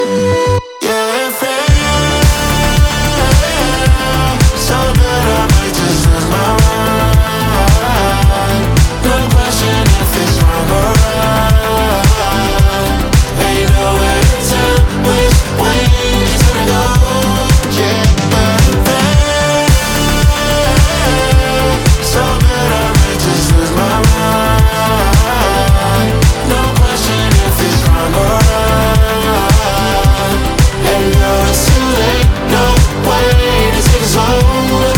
Жанр: Хип-Хоп / Рэп / Поп музыка